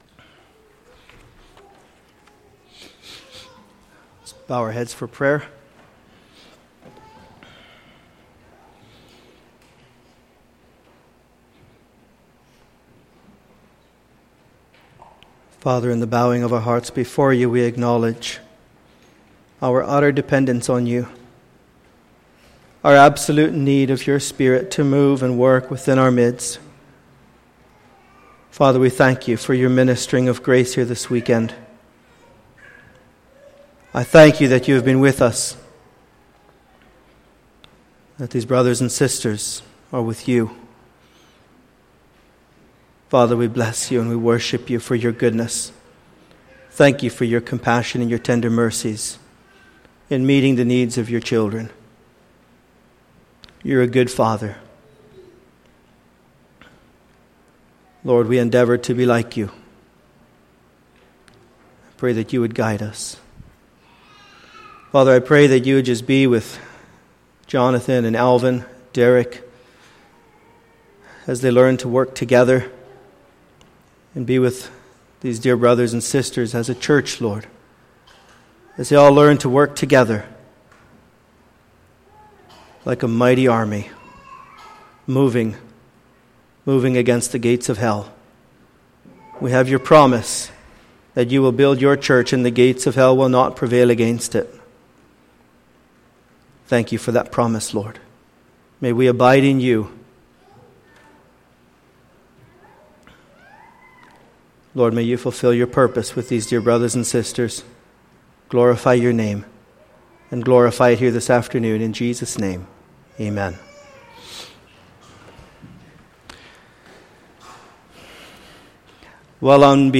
Ordanation 2014 Service Type: Sunday Afternoon %todo_render% « Ordination Message